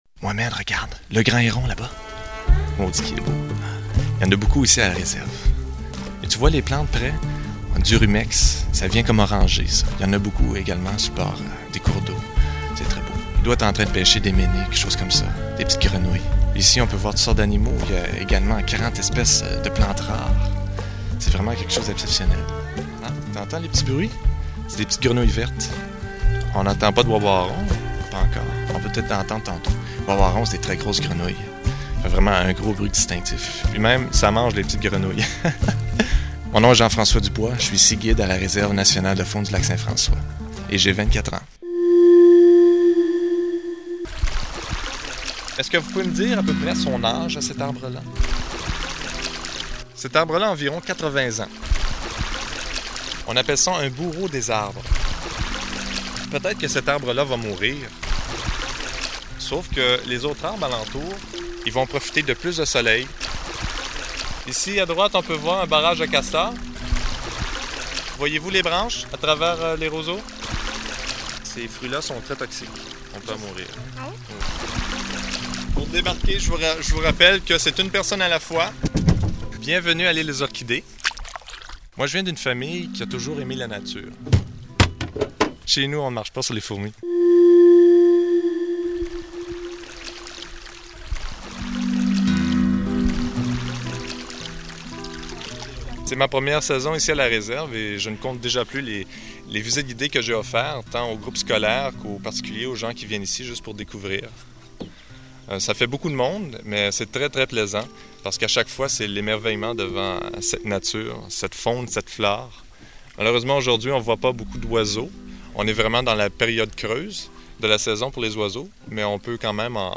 Reportage 11.07'